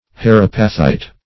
Search Result for " herapathite" : The Collaborative International Dictionary of English v.0.48: Herapathite \Her"a*path*ite\, n. [Named after Dr. Herapath, the discoverer.] (Chem.) The sulphate of iodoquinine, a substance crystallizing in thin plates remarkable for their effects in polarizing light.